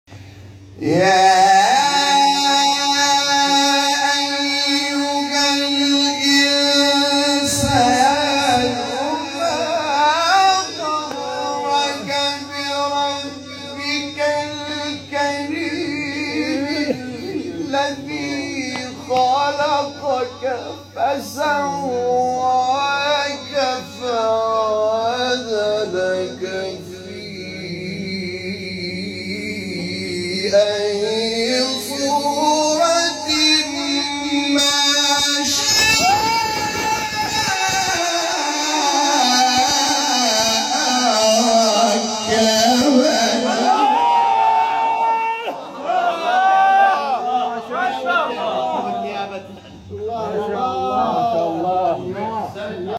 مقطع نهاوند استاد حامد شاکرنژاد | نغمات قرآن | دانلود تلاوت قرآن